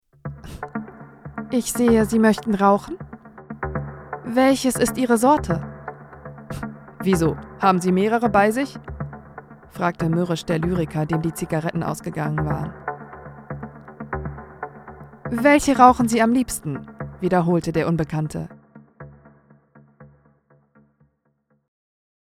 markant
Mittel minus (25-45)
Russian, Eastern European
Audiobook (Hörbuch), Audio Drama (Hörspiel)